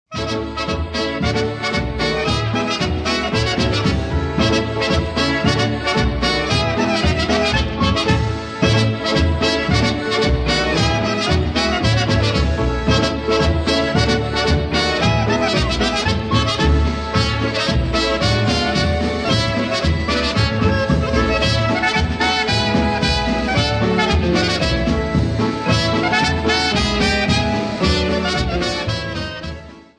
Media > Music > Polka, General